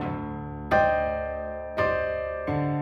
GS_Piano_85-D2.wav